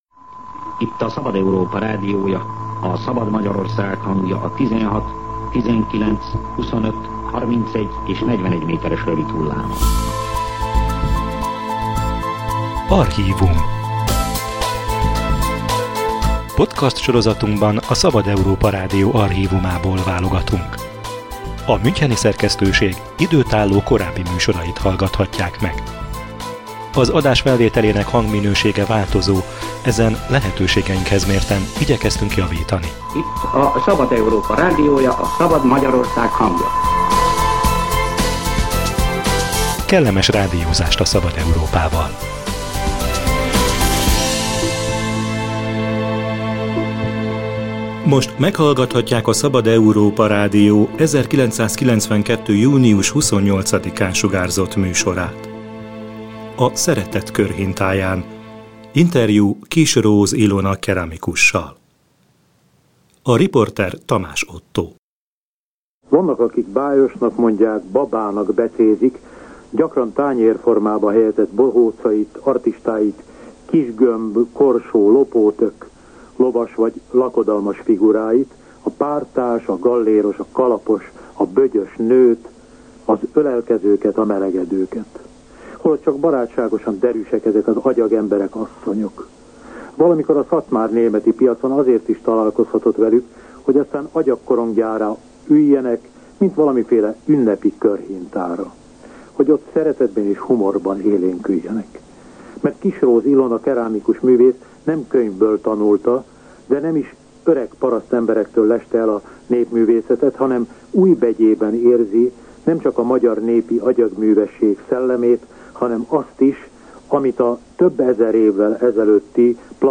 A szeretet körhintáján — archív beszélgetés